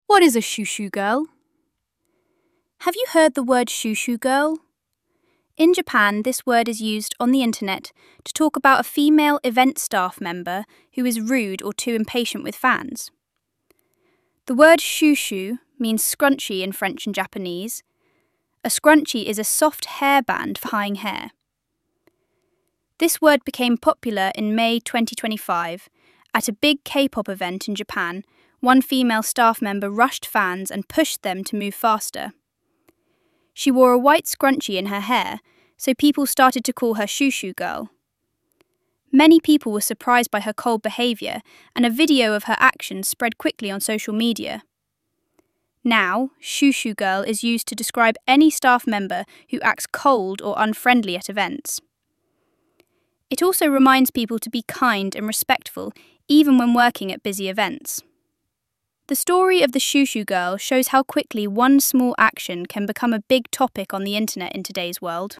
＜音読用音声＞